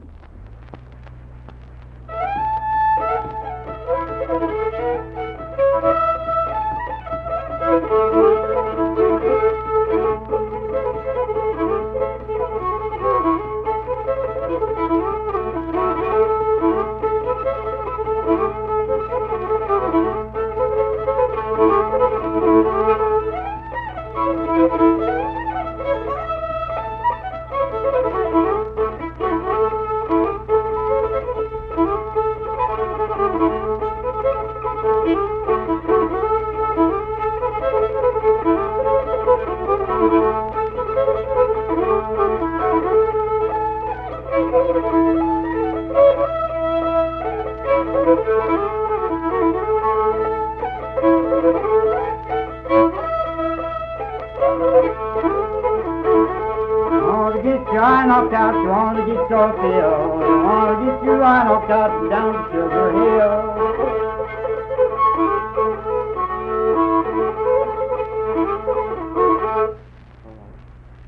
Unaccompanied vocal and fiddle music
Fiddle, Voice (sung)
Kirk (W. Va.), Mingo County (W. Va.)